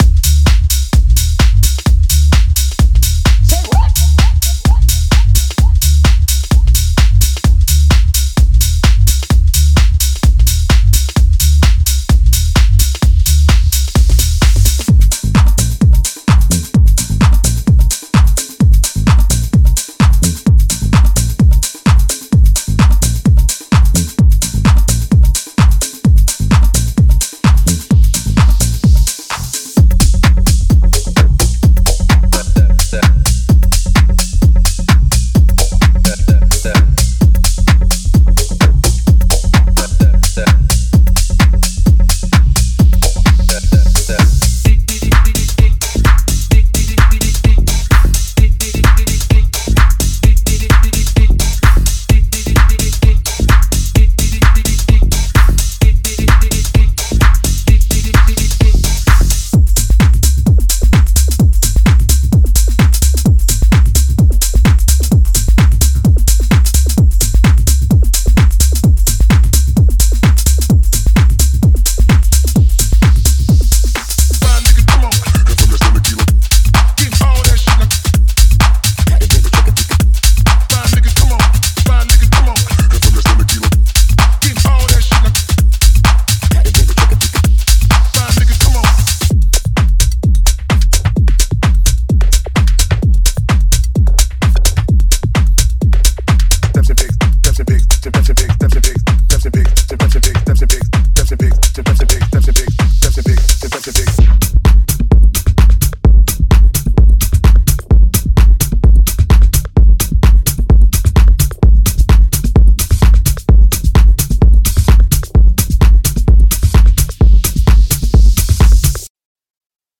Genre:Tech House
本パックは彼の多様な経験の本質を捉え、ライブドラムの温かみとグルーヴ感を、現代的な電子音楽の正確さとエネルギーと見事に融合させた豊富なループとサンプルを提供します。
20 Bass Loops
26 Perc Loops
12 Synth Loops
13 Vocals